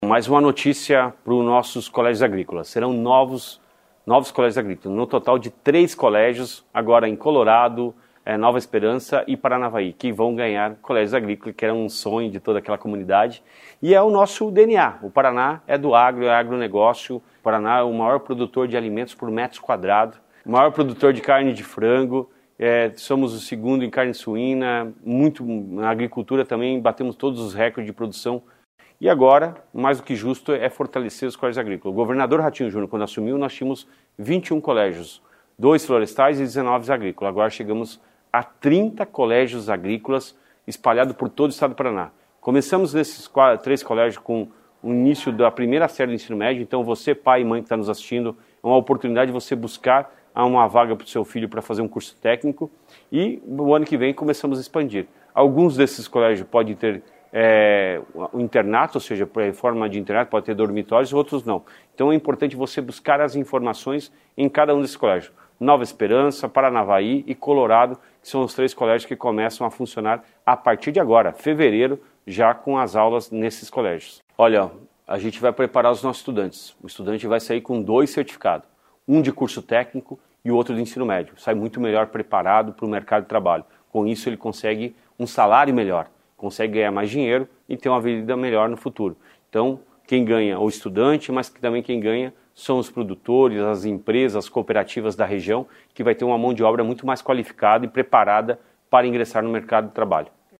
Sonora do secretário Estadual da Educação, Roni Miranda, sobre os novos colégios agrícolas no Noroeste